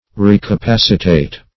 Meaning of recapacitate. recapacitate synonyms, pronunciation, spelling and more from Free Dictionary.
Search Result for " recapacitate" : The Collaborative International Dictionary of English v.0.48: Recapacitate \Re`ca*pac"i*tate\ (r[=e]`k[.a]*p[a^]s"[i^]*t[=a]t), v. t. To qualify again; to confer capacity on again.